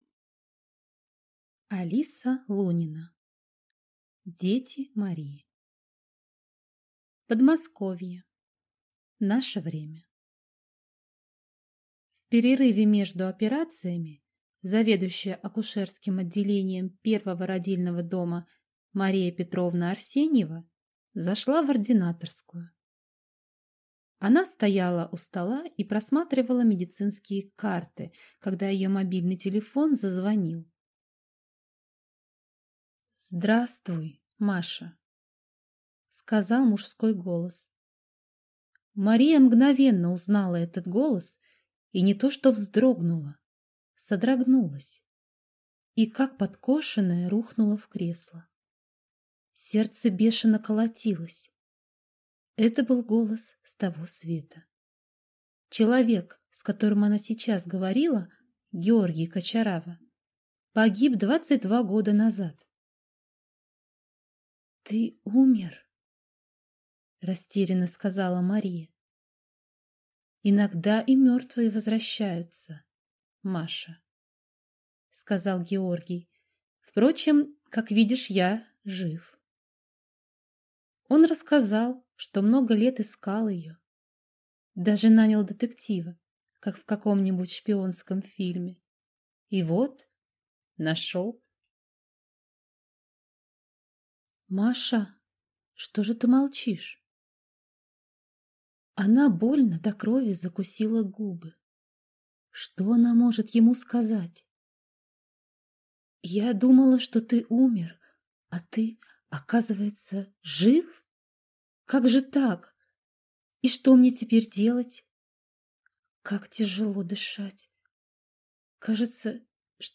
Аудиокнига Дети Марии | Библиотека аудиокниг